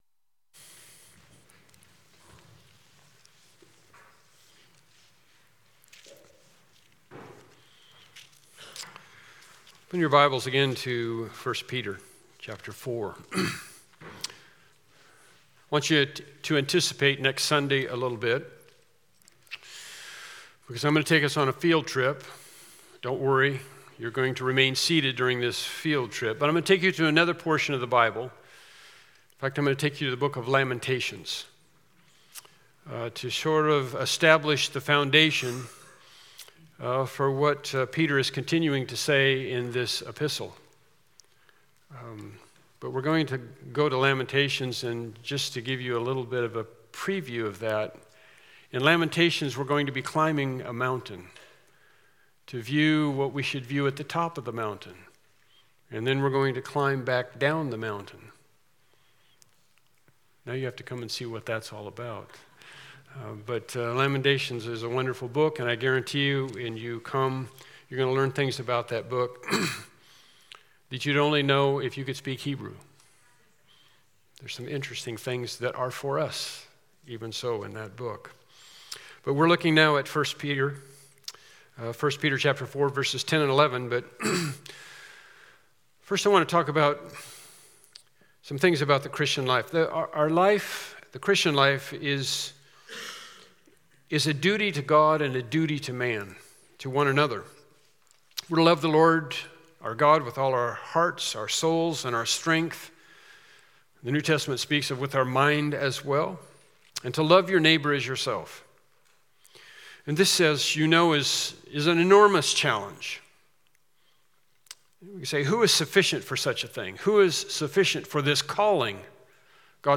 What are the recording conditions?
1 Peter 4:10-11 Service Type: Morning Worship Service « The People of the Millennium